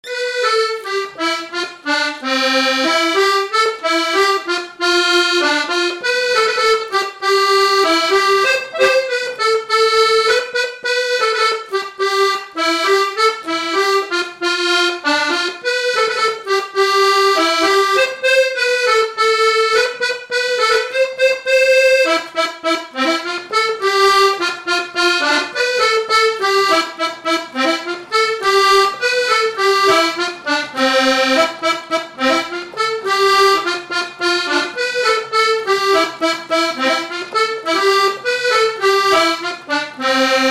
Polka
Résumé instrumental
danse : polka
Pièce musicale inédite